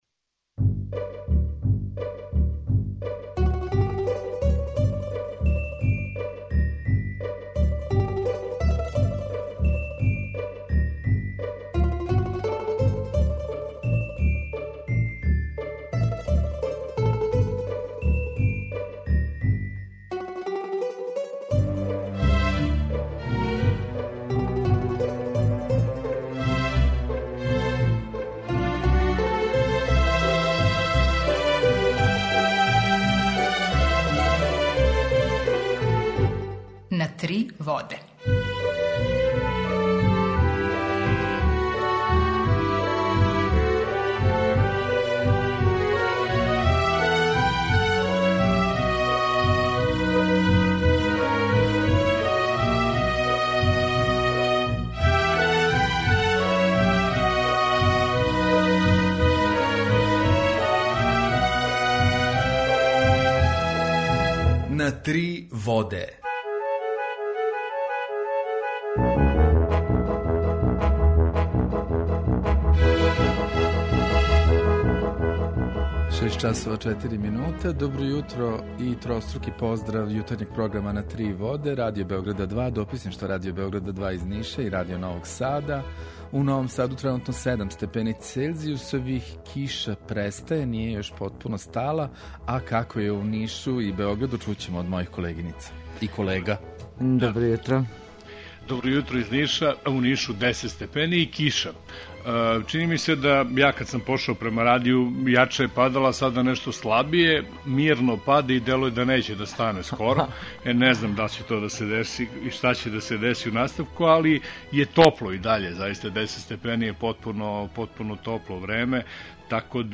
Укључење Радио Грачанице
Jутарњи програм заједнички реализују Радио Београд 2, Радио Нови Сад и дописништво Радио Београда из Ниша.